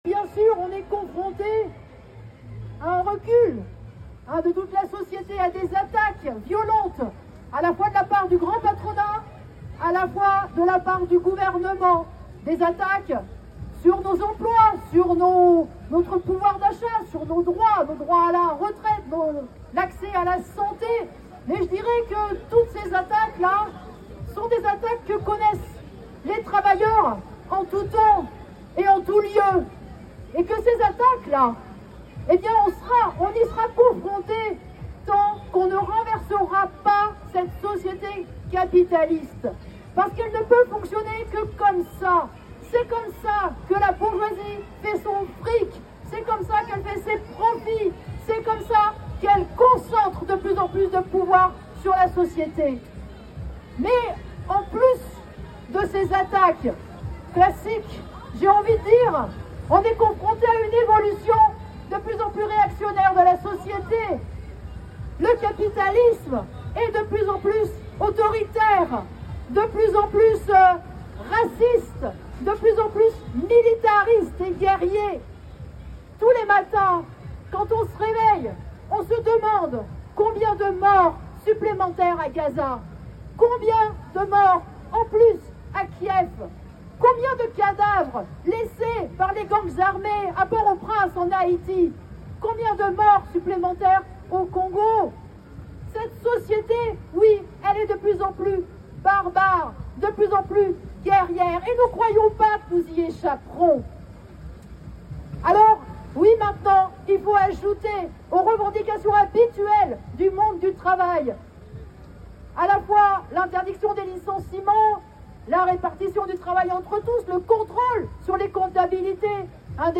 Manifestation du 1er mai - Intervention de Nathalie Arthaud